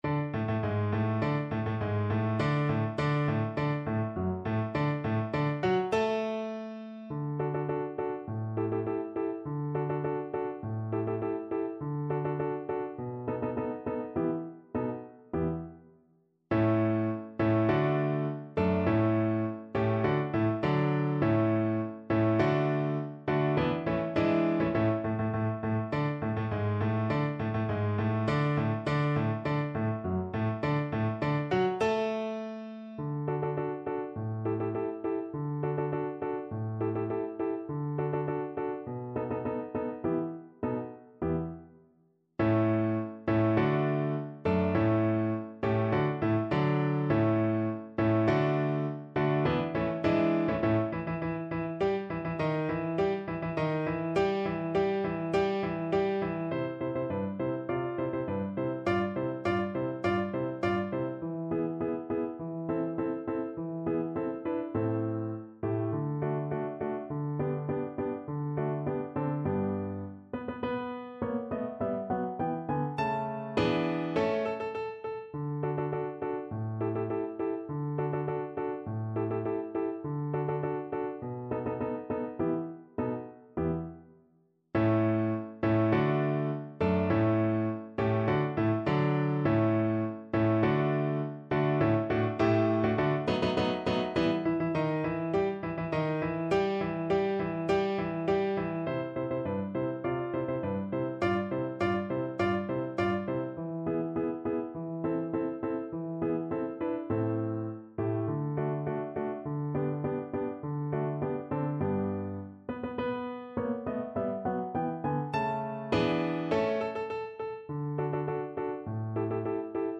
Schubert: Marsz wojskowy (na skrzypce i fortepian)
Symulacja akompaniamentu